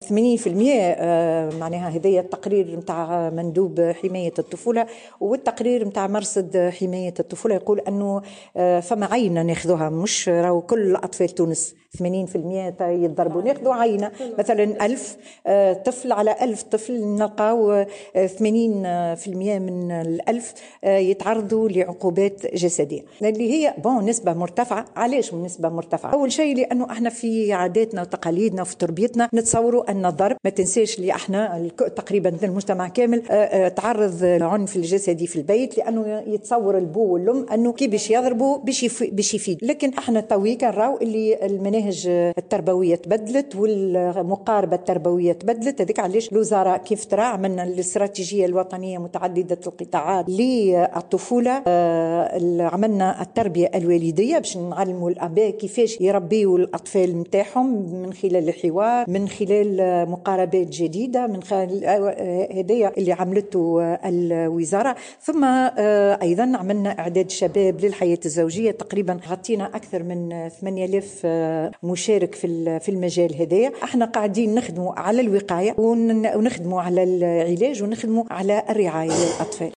أكدت وزيرة المرأة نزيهة العبيدي في تصريح لمراسلة الجوهرة "اف ام" أن تقرير مرصد حماية الطفولة أظهر تعرض 80 بالمائة من الأطفال يتعرضون للعنف في الوسط العائلي حسب العينة المختارة.